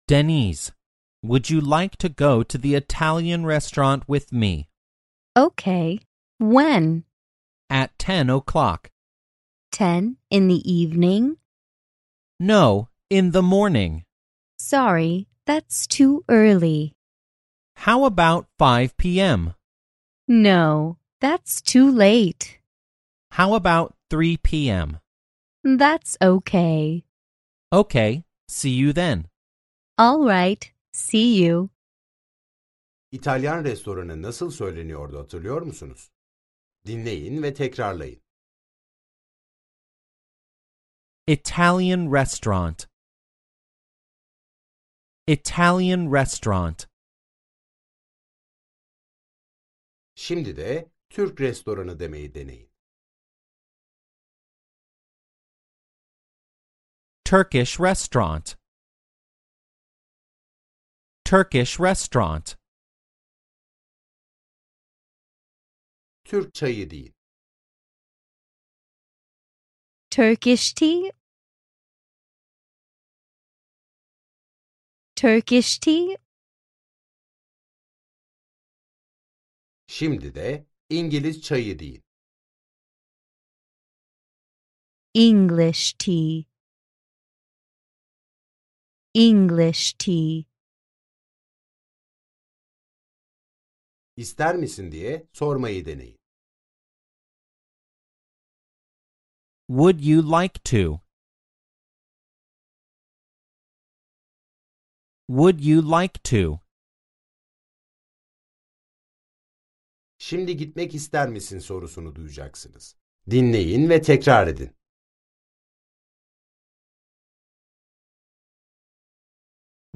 Az sonra İngilizce öğrenmenin keyifli dünyasına adım atacaksınız. Dersler boyunca sizi yönlendiren bir kişisel eğitmeniniz olacak. Ana dili İngilizce olan iki kişi de sürekli diyalog halinde olacaklar.